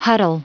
Prononciation du mot huddle en anglais (fichier audio)
Prononciation du mot : huddle